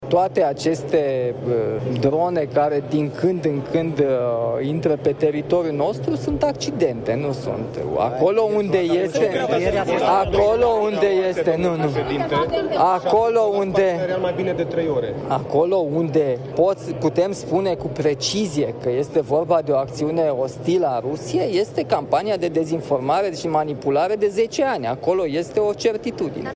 Declarații pe holurile parlamentului, venite de la șeful statului, după ce a prezentat Strategia Națională de Apărare. Președintele Nicușor Dan spune că intrarea dronelor în spațiul aerian românesc ar fi „accidente” și că provocările Rusiei sunt de altă natură.